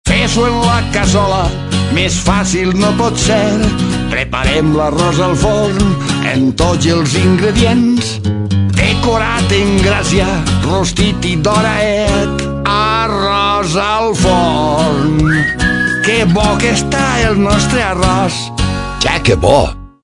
Siempre los últimos TONO DE ANUNCIOS